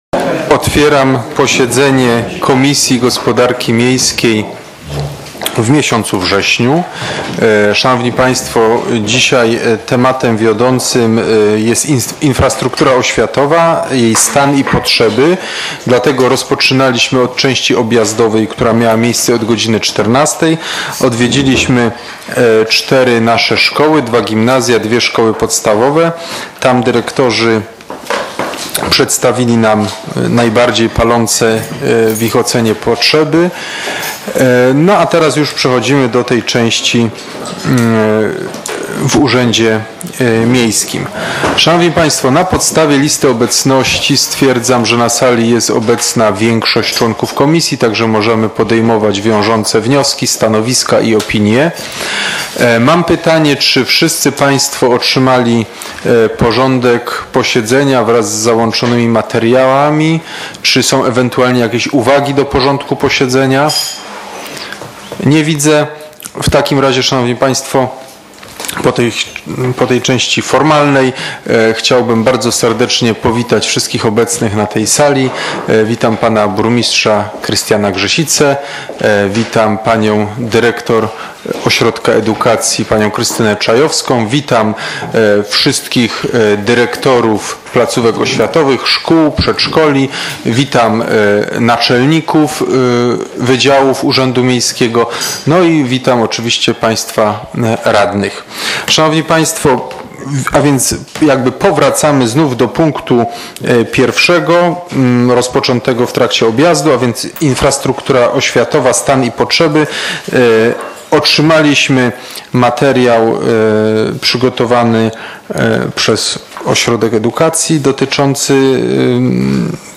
z posiedzenia Komisji Gospodarki Miejskiej w dniu 20.09.2016 r.